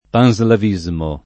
[ pan @ lav &@ mo ]